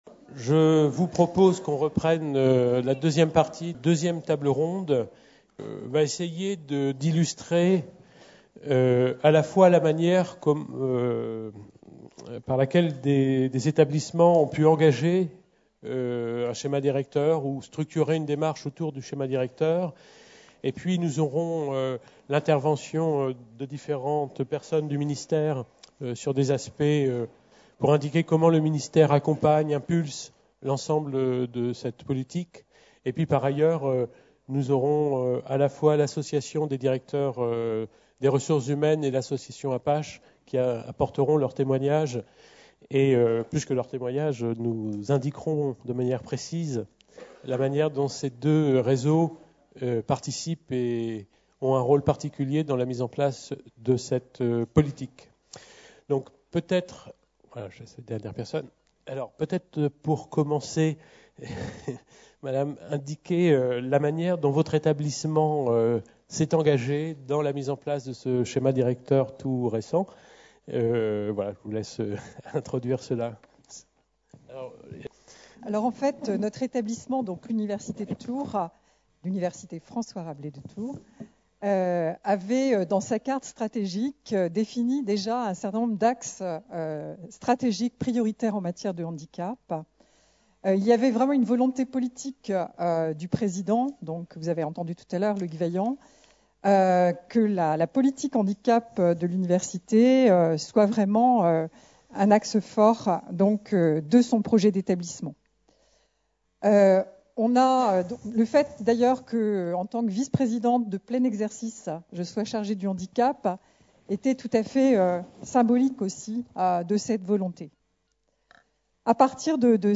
Table ronde 2 : Le schéma directeur handicap et sa déclinaison dans un établissement d’enseignement supérieur et de recherche | Canal U